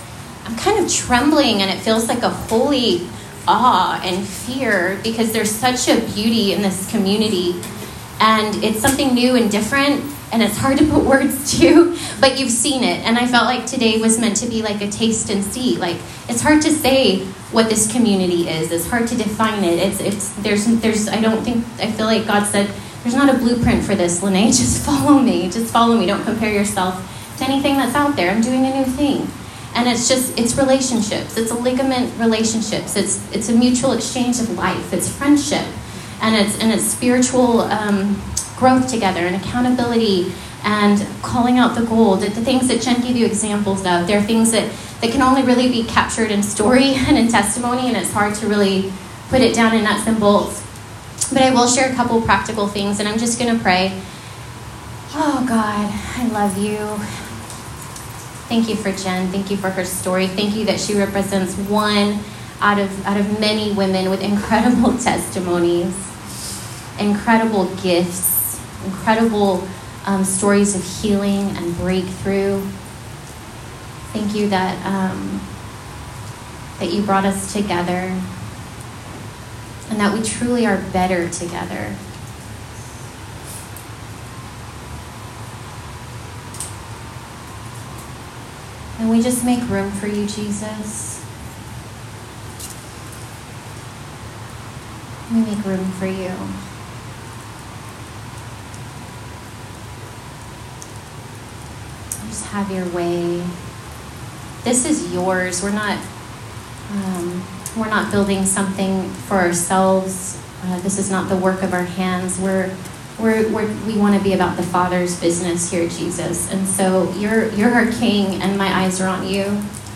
You can hear my heart and learn more about our community by listening to this recording taken from a recent gathering.